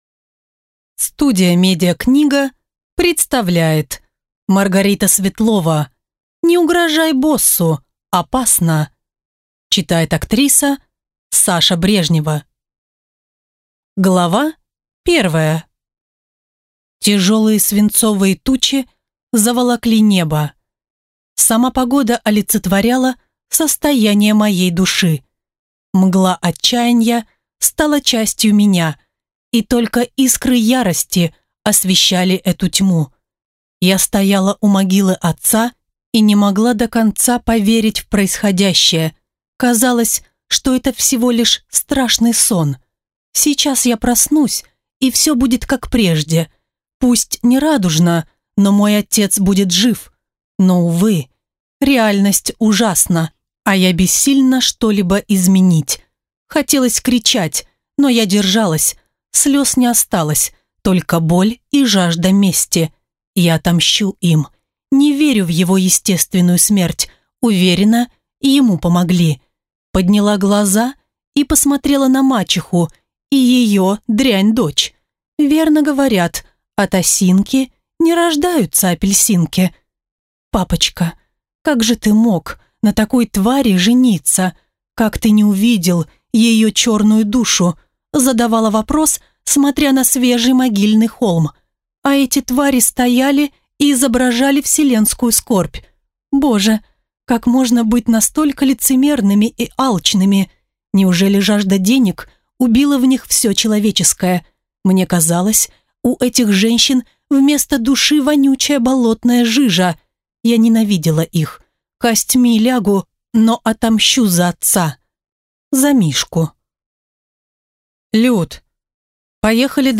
Aудиокнига Не угрожай боссу, опасно!